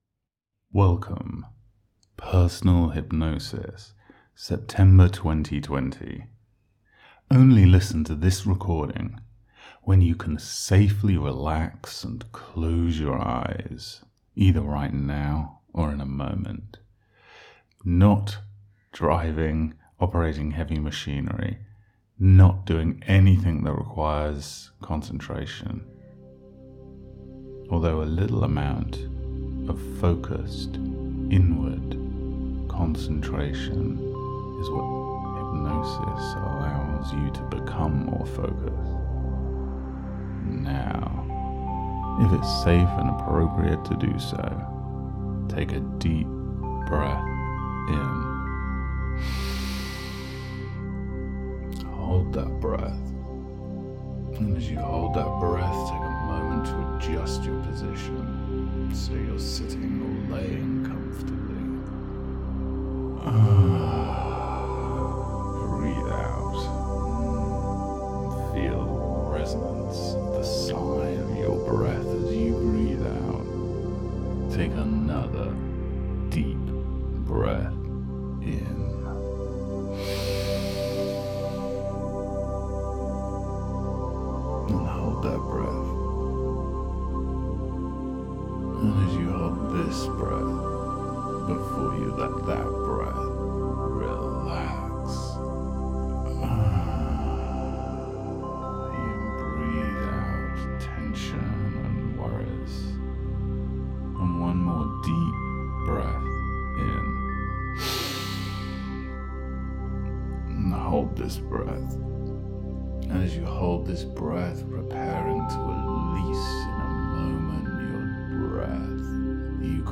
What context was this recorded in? Listen to this 'Live' Personal Hypnosis session.